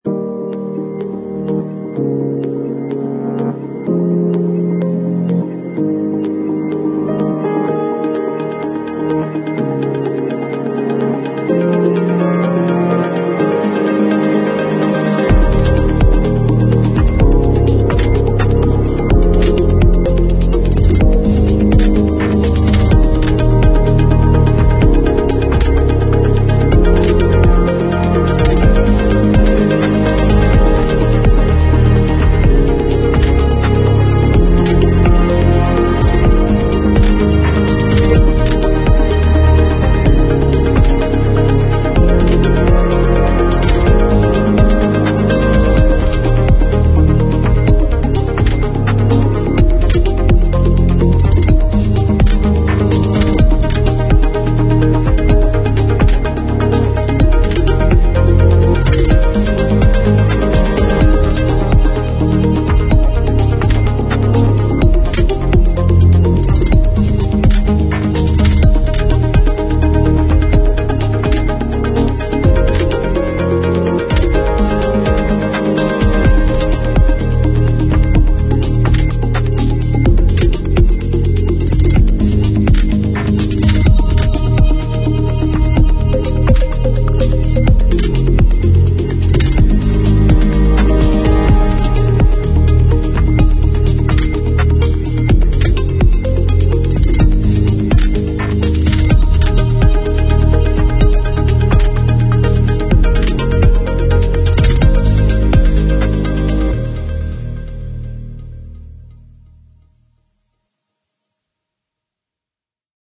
描述：是一个美丽的电子轨道适合现代和创意项目。
Sample Rate 采样率16-Bit Stereo 16位立体声, 44.1 kHz